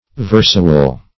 Versual \Ver"su*al\, a. Of or pertaining to a verse.